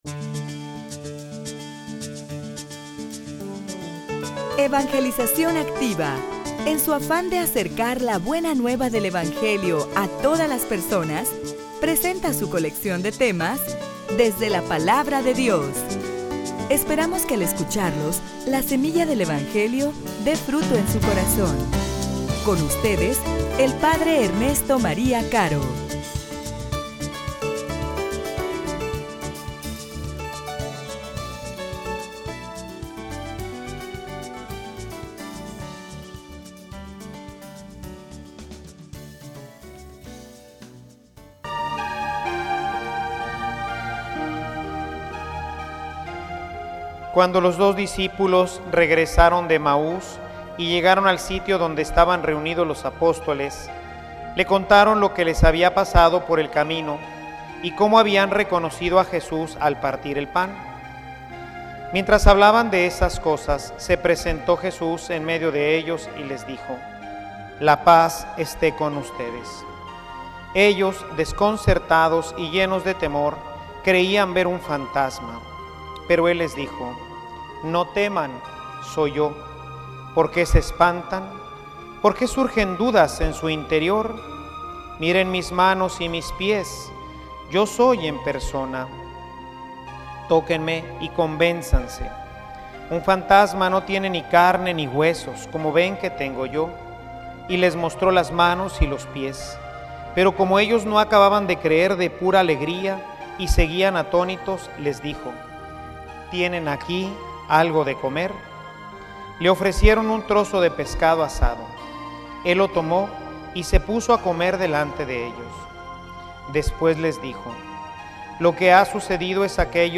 homilia_Que_tu_fe_testifique_al_resucitado.mp3